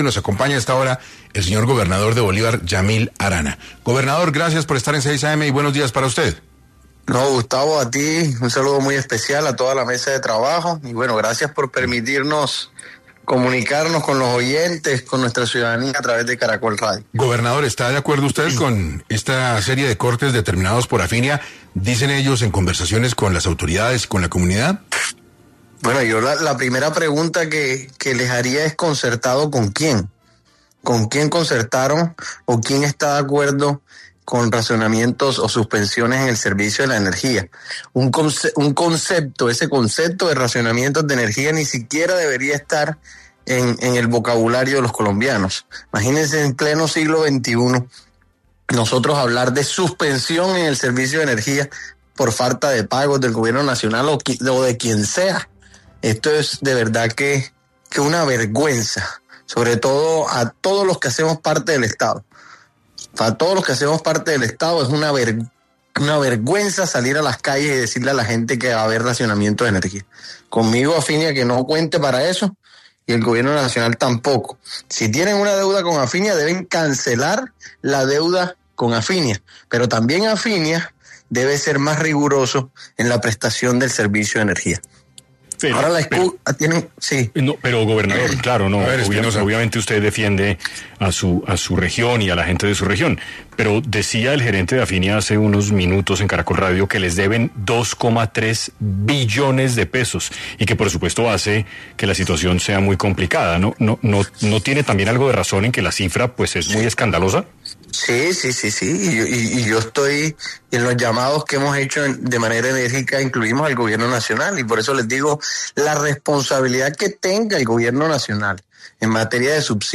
Yamil Arana, gobernador de Bolívar, estuvo en 6AM para responder a las razones por las que Afinia asegura que son necesarios los cortes de energía.
En este contexto, Yamil Arana, gobernador de Bolívar, pasó por los micrófonos de 6AM para abordar este problema y exponer su punto de vista sobre la coyuntura que actualmente afecta a los hogares de este territorio.